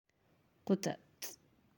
(quṯuṭ)